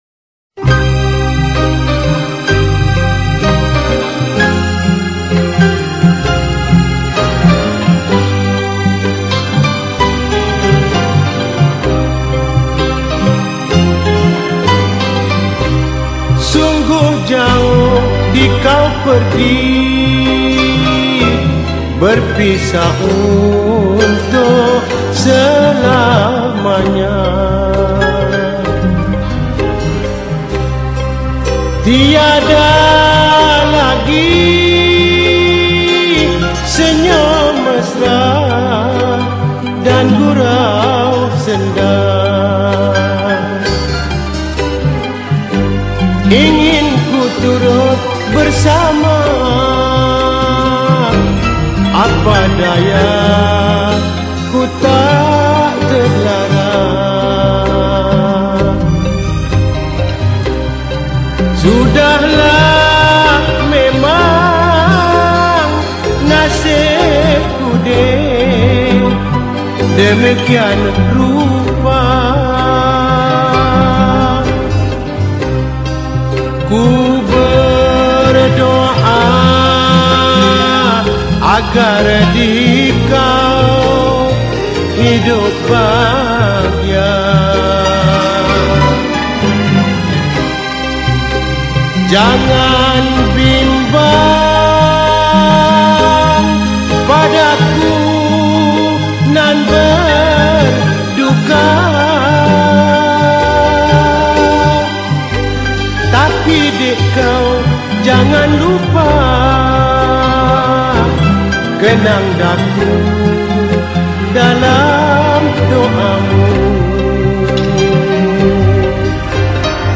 Malay Song